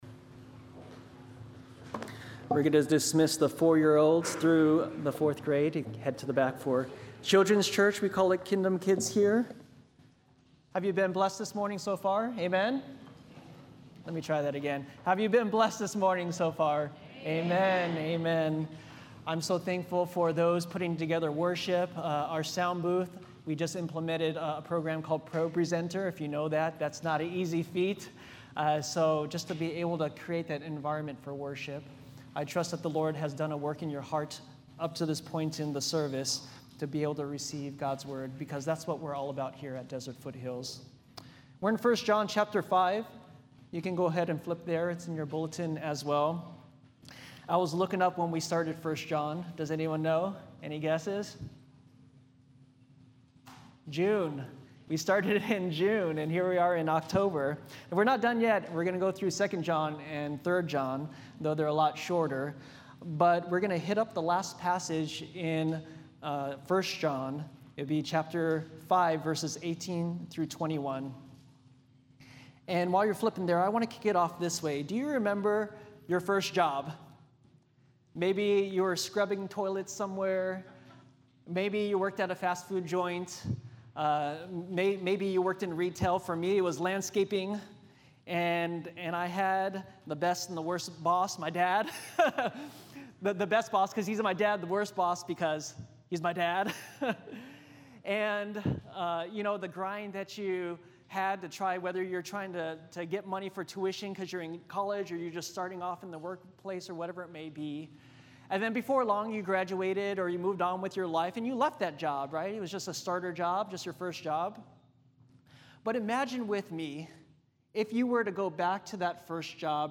SERMONS | Desert Foothills Baptist Church